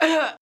damage1.wav